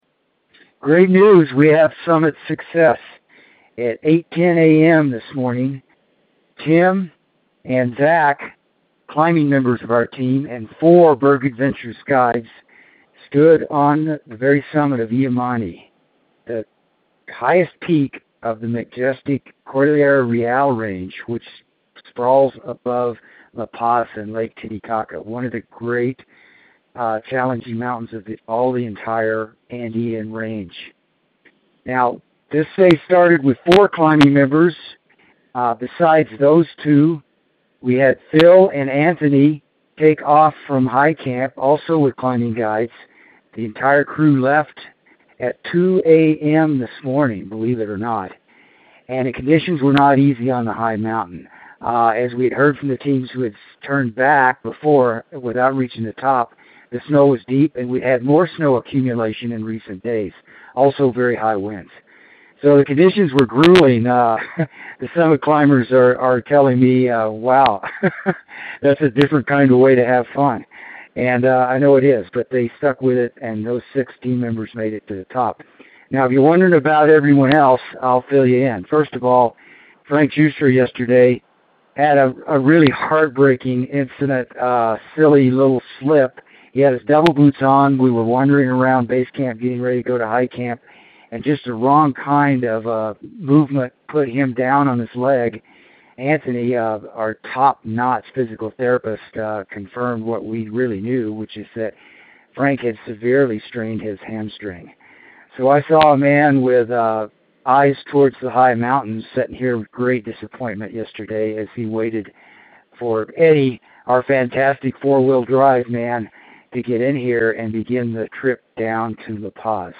Voice Dispatch Part 1